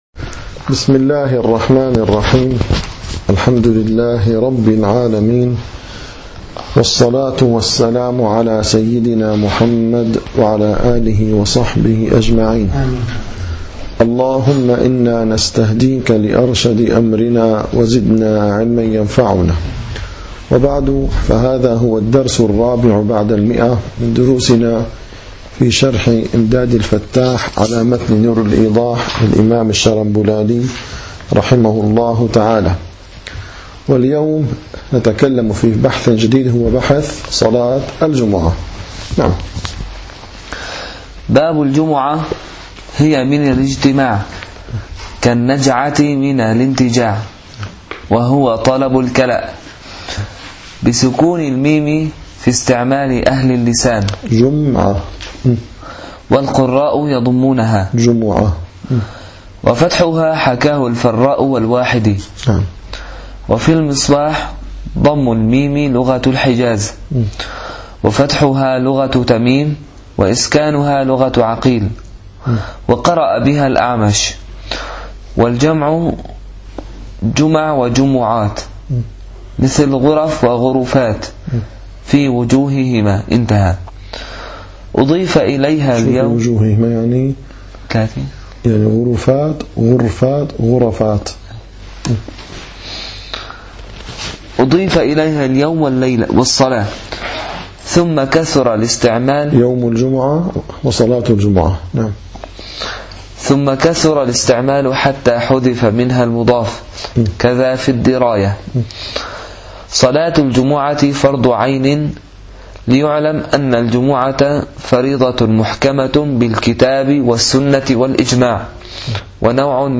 - الدروس العلمية - الفقه الحنفي - إمداد الفتاح شرح نور الإيضاح - 104- باب الجمعة